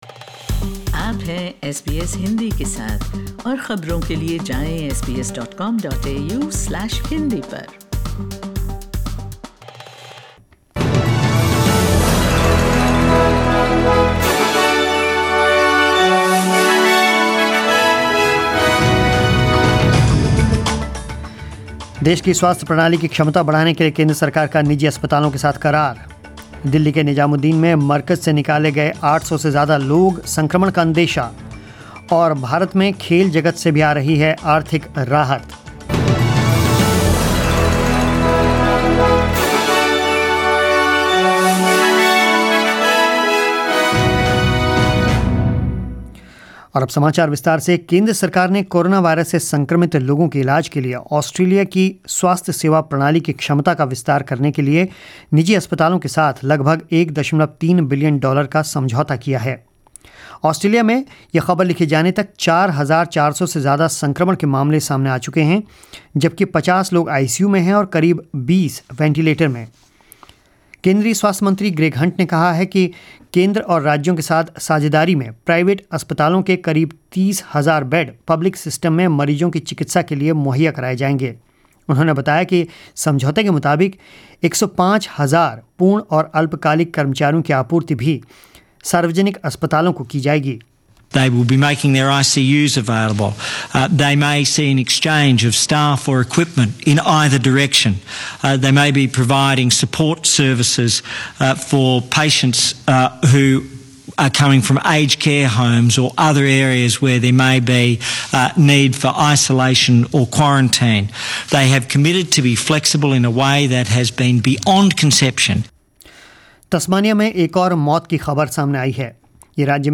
News in Hindi 31 March 2020